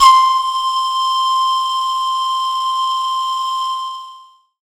It features a mixture of field and studio recordings and programming for
Tabla,
Oriental Percussion,